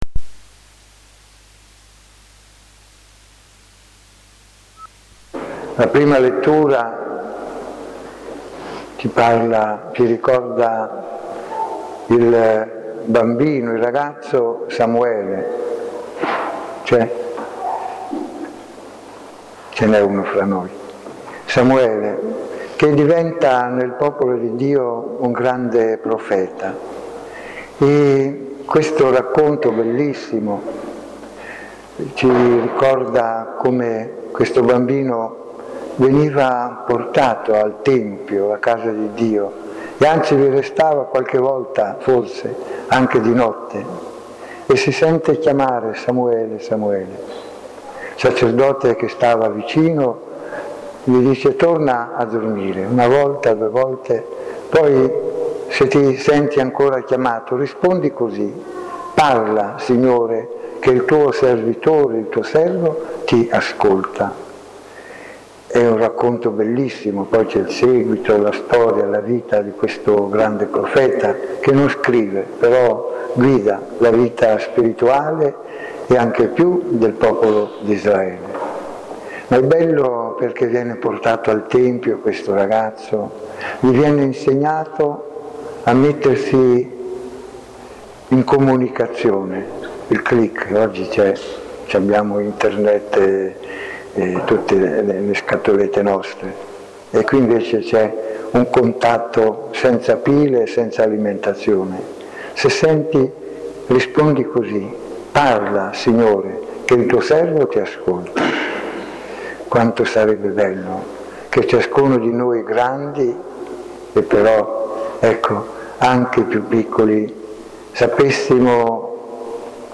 15 febbraio 2015 - In San Ciriaco ore 18
Prima Omelia di S.Em.Mons Edoardo Menichelli Neocardinale